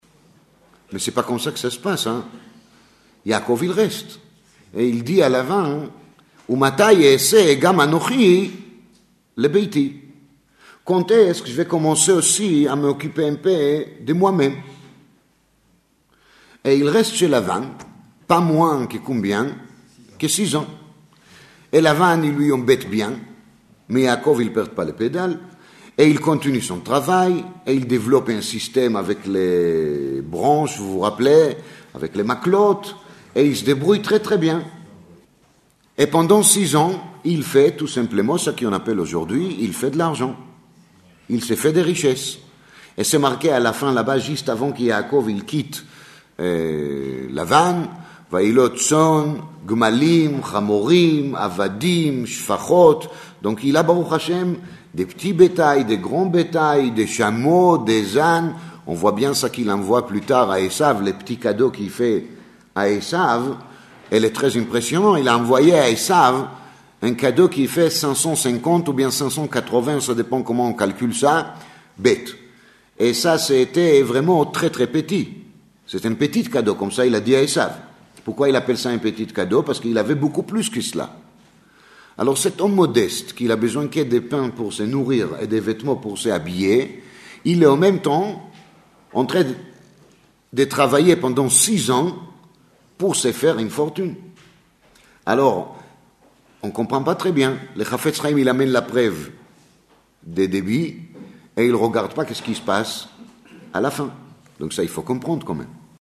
Il avait été édité en studio voici de nombreuses années, mais curieusement il n’a été publié que 29 ans plus tard.
Nous en avons profité pour affiner encore le premier montage réalisé alors, au temps où nous diffusions les cours en cassettes audio.